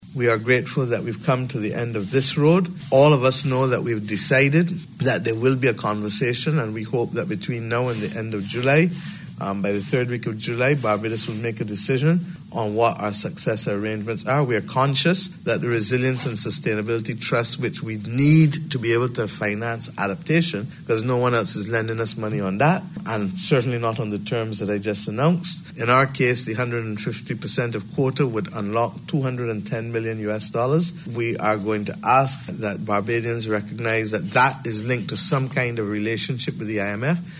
This has been disclosed by Prime Minister Mia Amor Mottley at joint news conference with the visiting Managing Director of the International Monetary Fund Kristalina Georgieva .